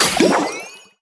safe_open_elixir_01.wav